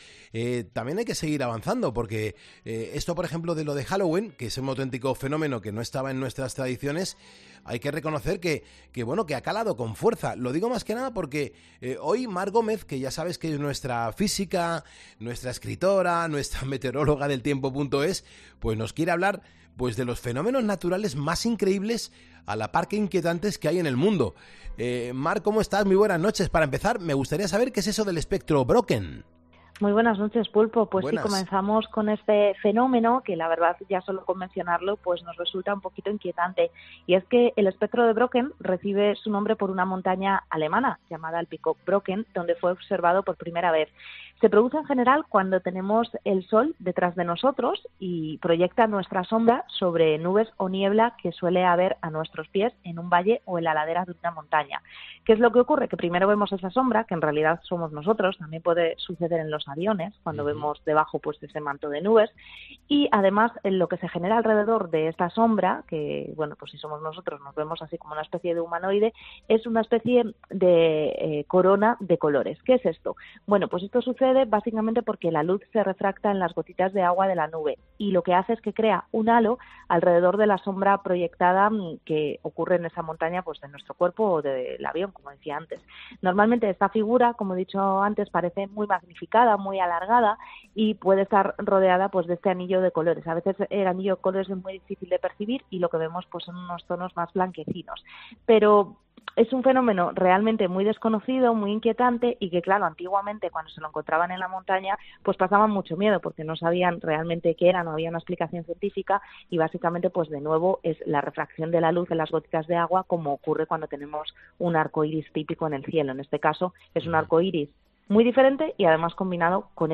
La meteoróloga